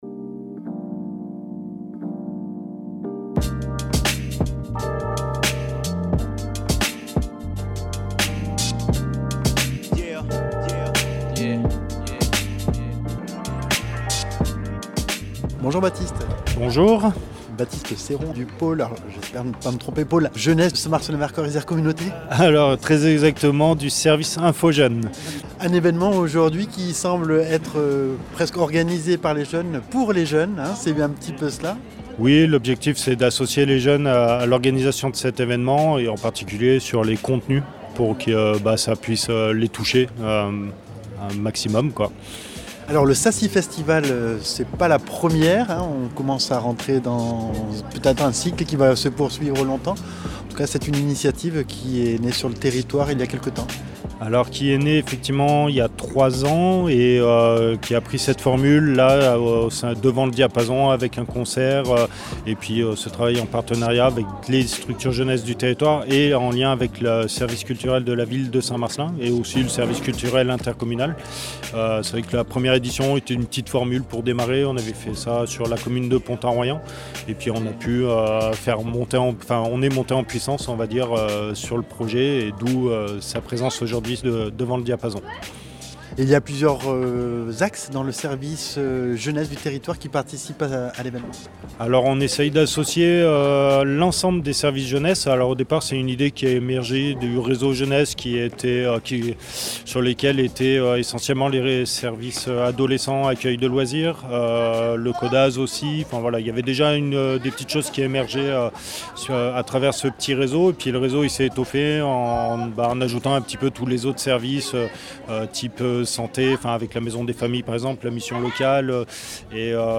Tour d’horizon dans le bouillonnement du festival avec celles et ceux qui ont contribué à cet événement s’instituant durablement sur le territoire.